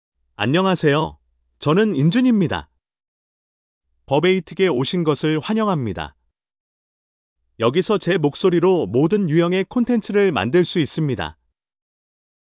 InJoonMale Korean AI voice
InJoon is a male AI voice for Korean (Korea).
Voice sample
Listen to InJoon's male Korean voice.
Male